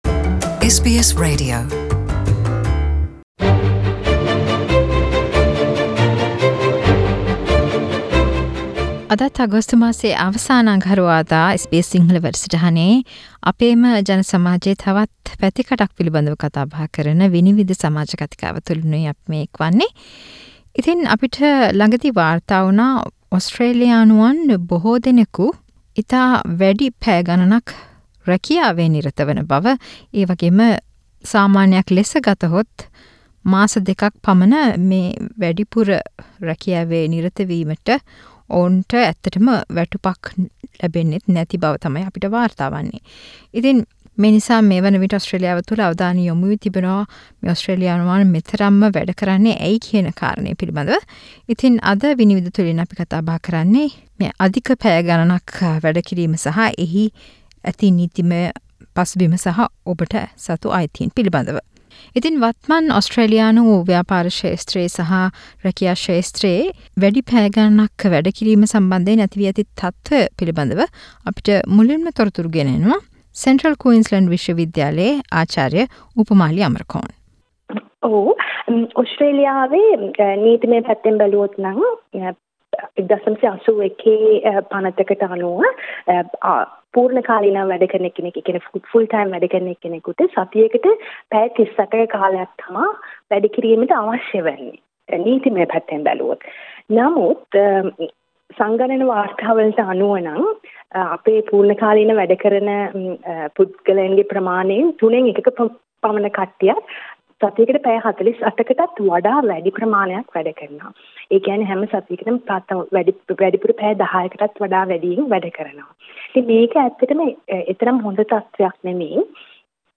SBS සිංහල මාසයේ අවසාන අගහරුවාදා ගෙන එන "විනිවිද " සමාජ කතිකාව තුලින් මෙවර අවධානය යොමු කරන්නේ වේ සේවක පිරිස් වැඩි පැය ගණනක් වැටුප් රහිතවම සේවය කිරීම හා ඒ සම්බන්ධයෙන් නීතිමයව අපිට ඇති අයිතීන් පිලිබදවයි.